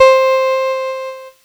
Cheese Note 06-C3.wav